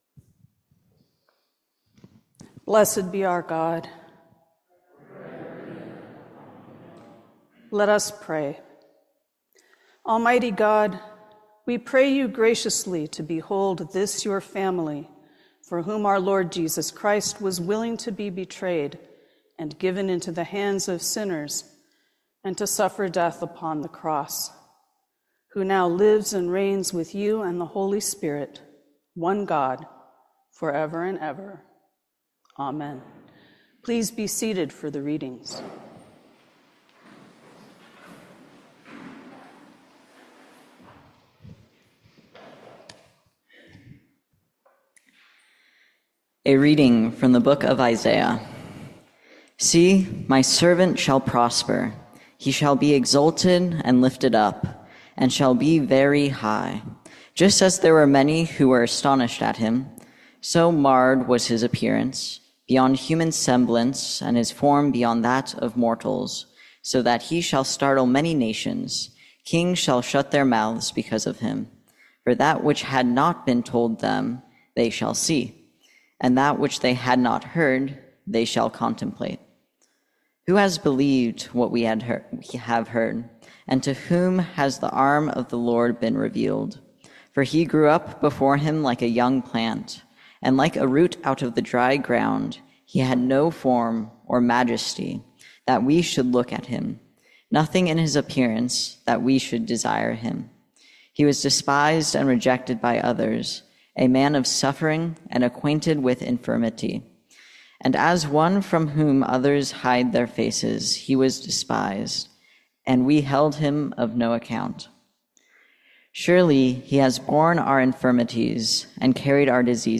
Audio recording of the 7pm hybrid/streamed service